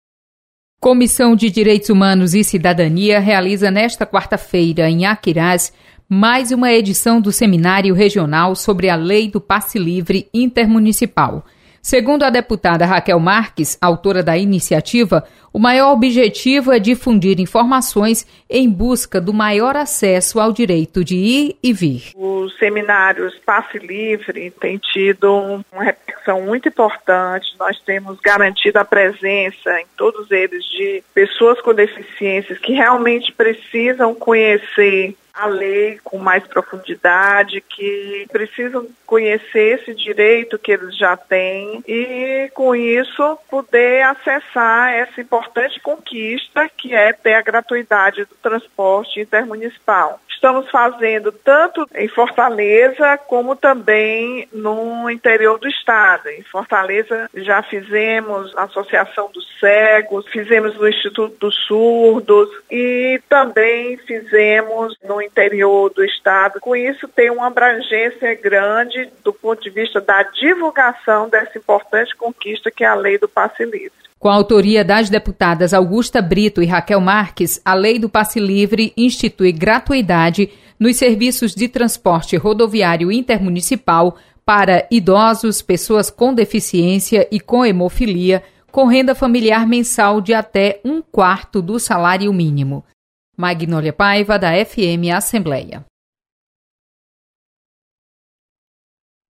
Comissão divulga Lei do Passe Livre em Aquiraz. Repórter